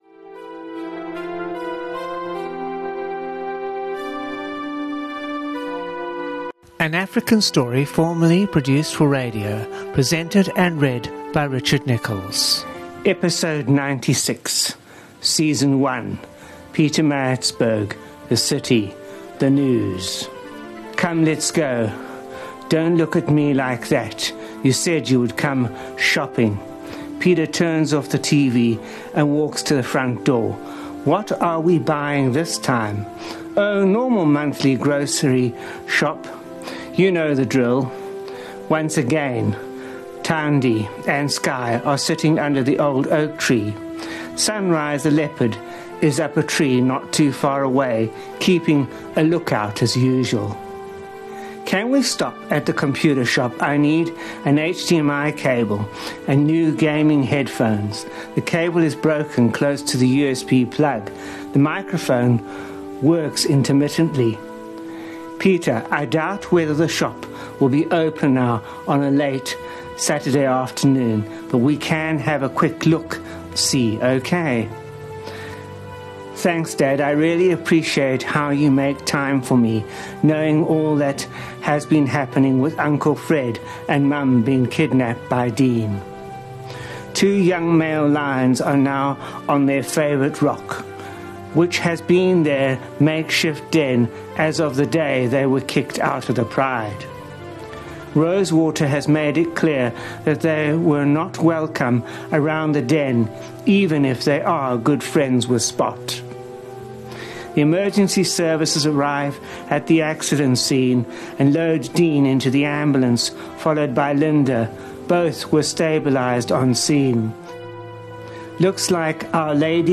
The Park An African Story produced for Community Radio.
Kids And Families